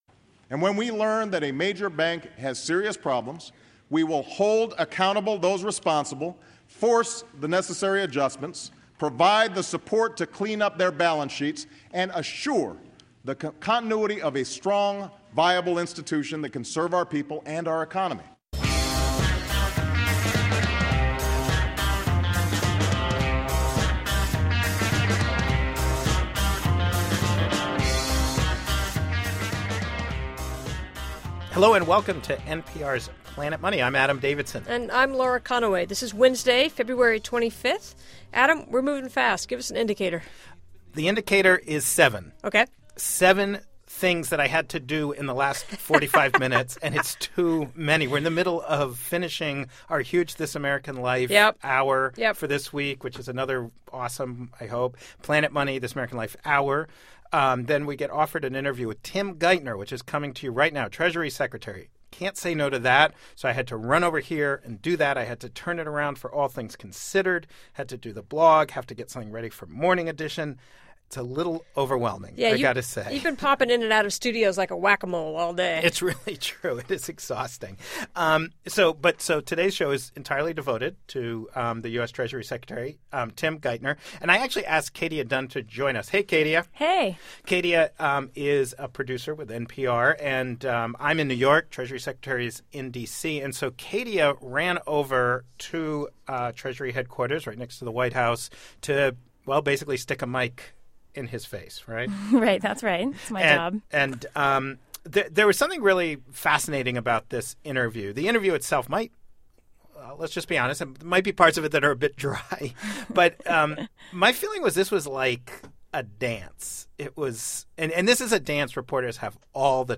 As you'll hear, the pairing of titan and reporter made for quite a dance.